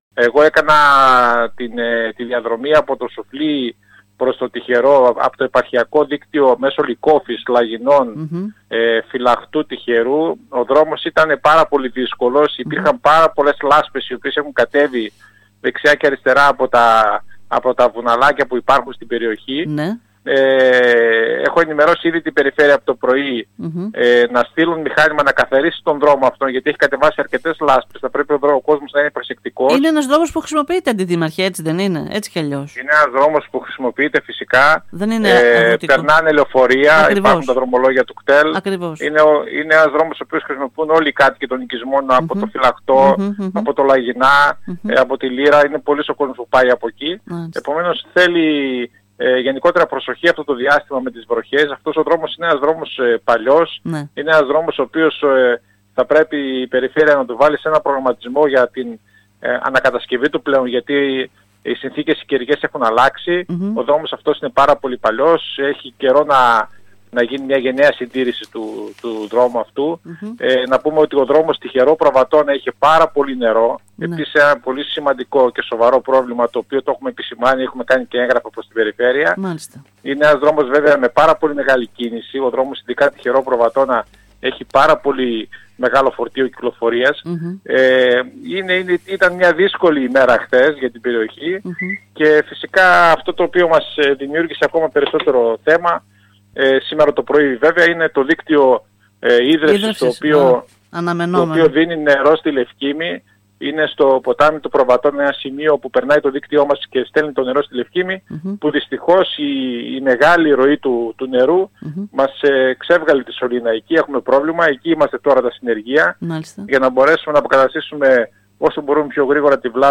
Ο αρμόδιος Αντιδήμαρχος Δημήτρης Δαρούσης μιλώντας σήμερα στην ΕΡΤ Ορεστιάδας ανέφερε τα προβλήματα που δημιουργήθηκαν στην Ιρλανδική διάβαση του δημοτικού δρόμου που συνδέει την Λύρα με τα Λαγυνά, λόγω των μεγάλων ποσοτήτων νερού, που όμως  γρήγορα υποχώρησαν..